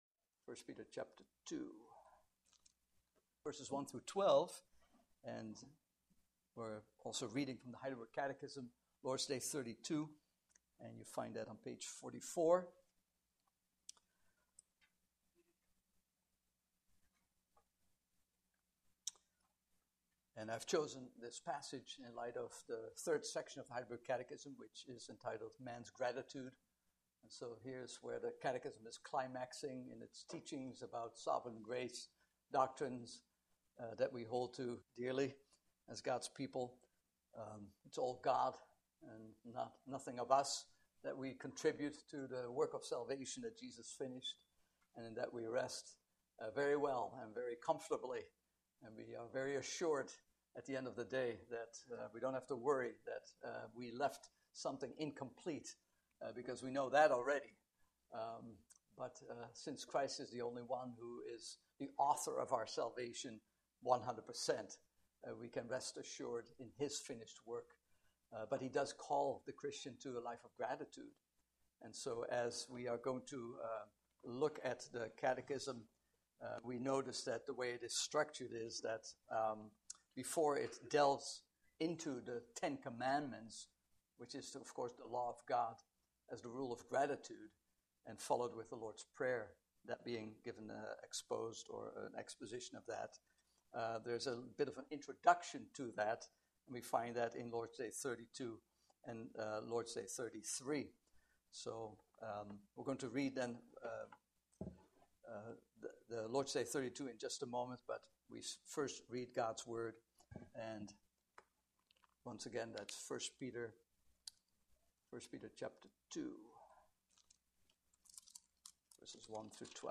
Passage: I Peter 2:1-12 Service Type: Evening Service